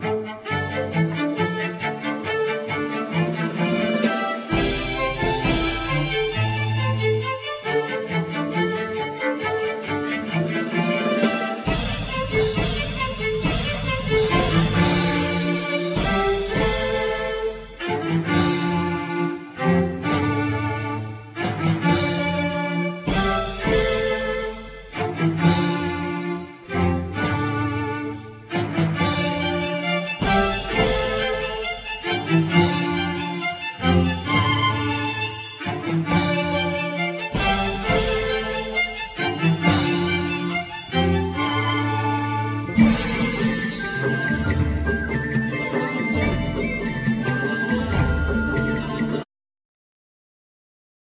Vocals
Ghironda,Salterio
Viola da Gamba,Ribeca
Flauto dolce,Flauto indiana,Flauto piccolo
Lute,Ud ,Mandlin
Arpa celtica